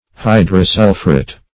Hydrosulphuret \Hy`dro*sul"phu*ret\, n.
hydrosulphuret.mp3